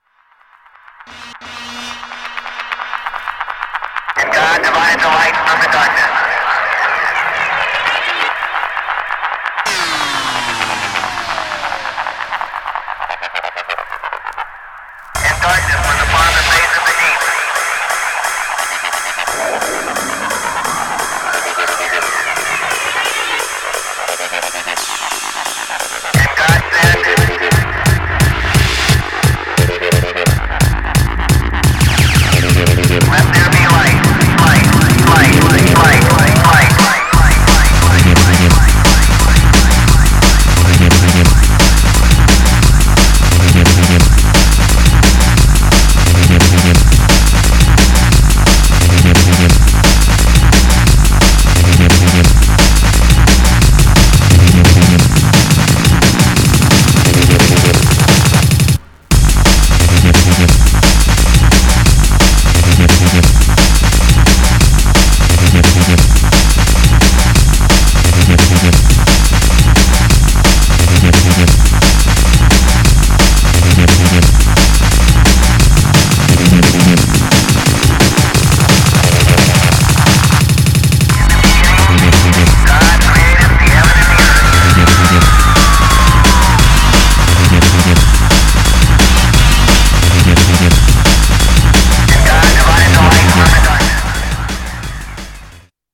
Styl: Drum'n'bass, Jungle/Ragga Jungle